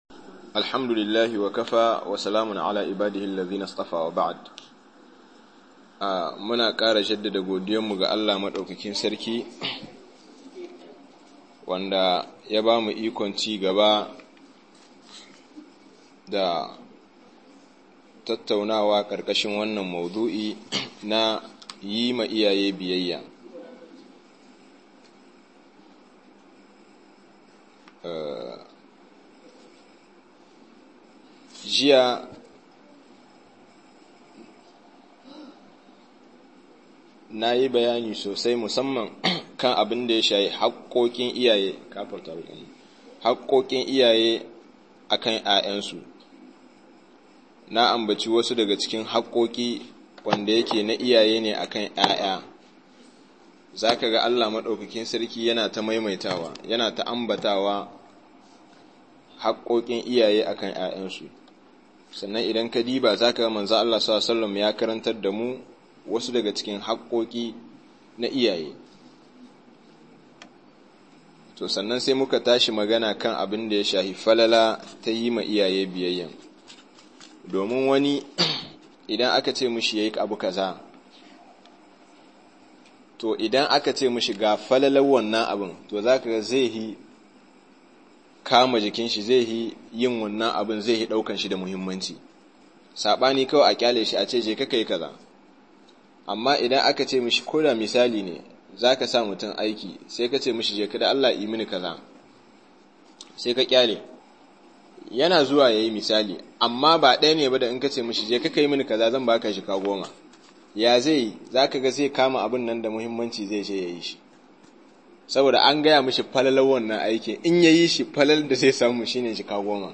biyayya ga iyaye-02 - Muhadara